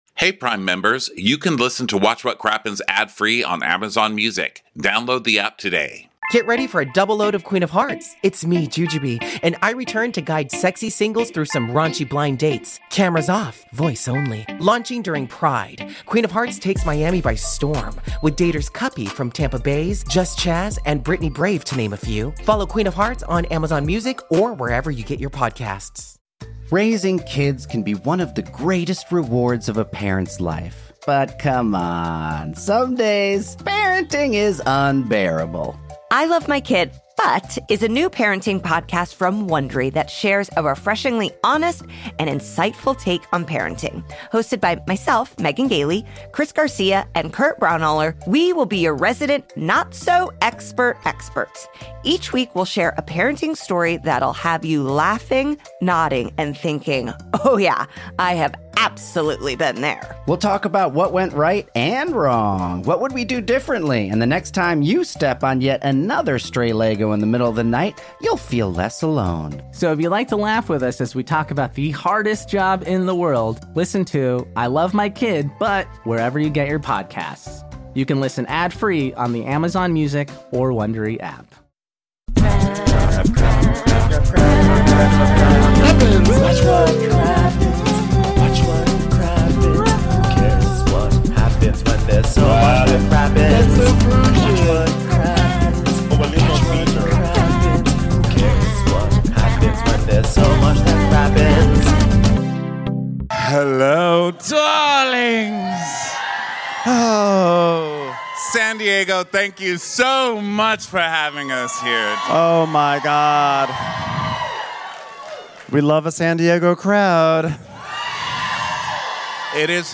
RHOC Live in San Diego: The Betch is Back, Betch!